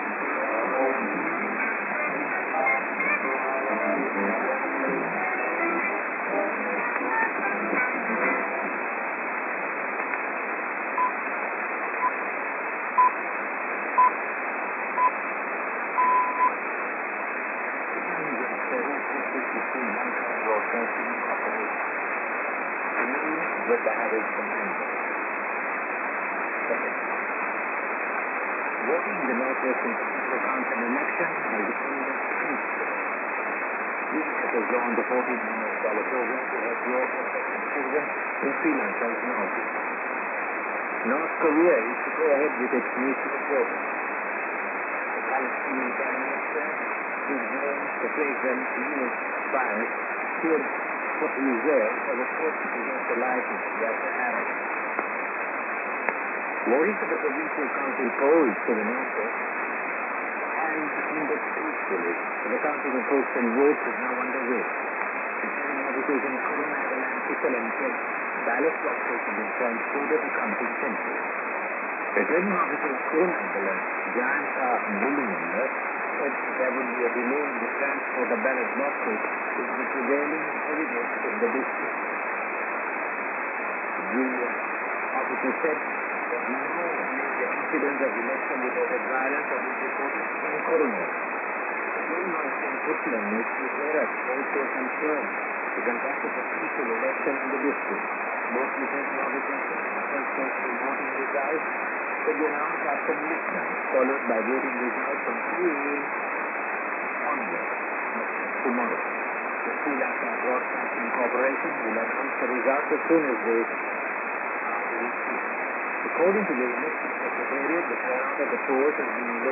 music->TS->ANN(man:ID)->news->ID